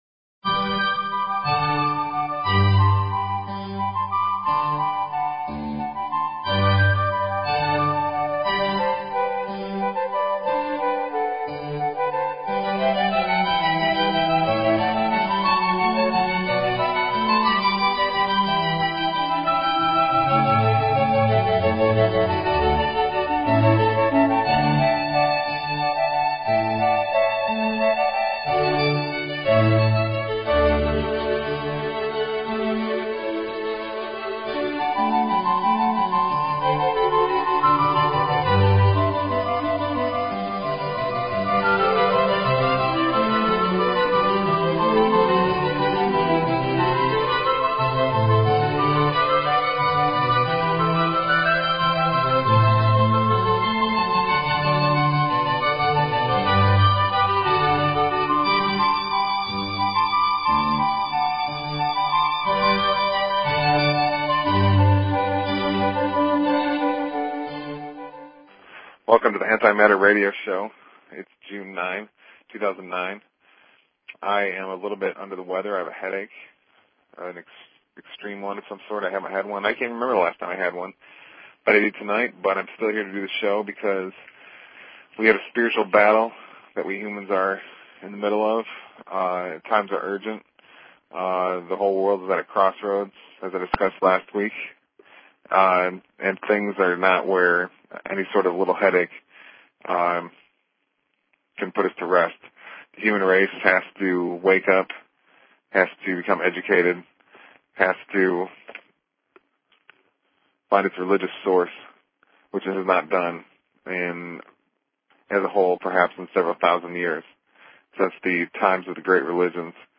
Talk Show Episode, Audio Podcast, The_Antimatter_Radio_Show and Courtesy of BBS Radio on , show guests , about , categorized as